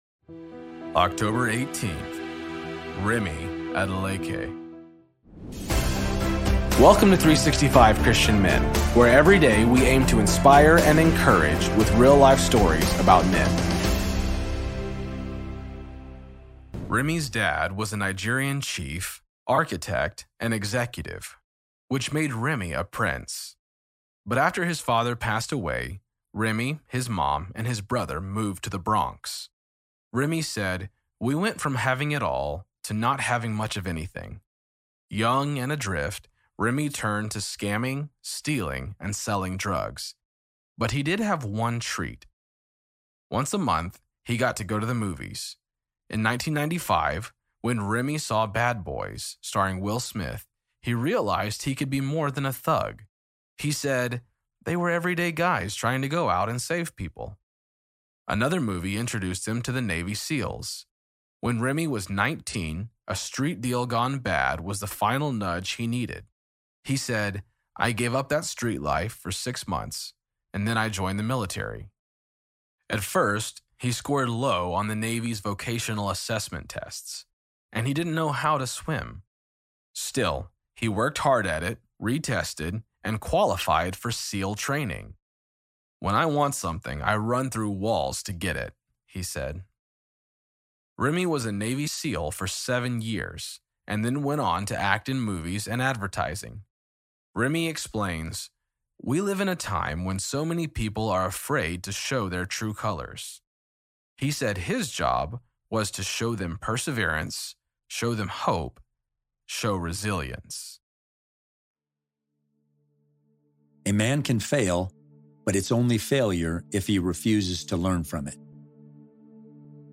Story read by:
Introduction read by: